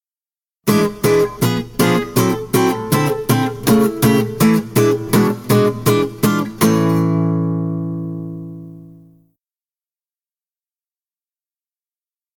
Это упражнение играется только даунстроком - ударами медиатора от себя (вниз).
Дополнительно они придают звучанию небольшой налет винтажа – примерно так играли большинство гитарных партий в 1920-1930-х в эпоху биг-бендов.